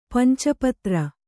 ♪ panca patra